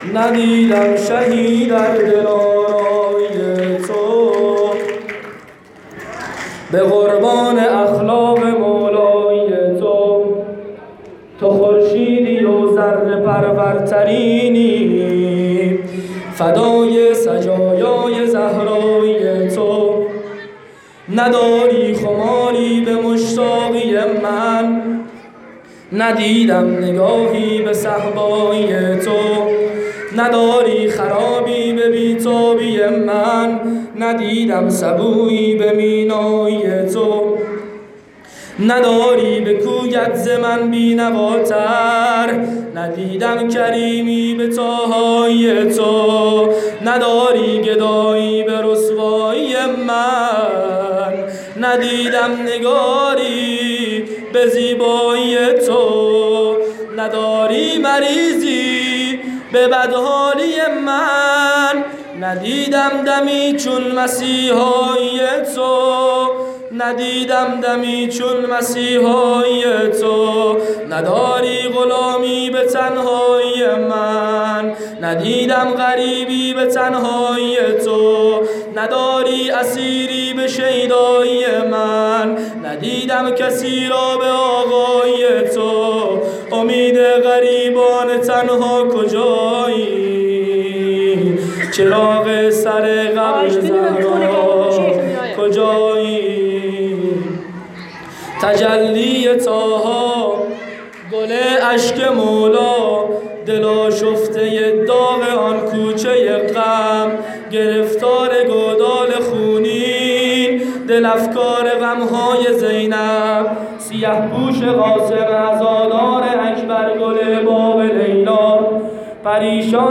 مراسم جشن نیمه شعبان۹۷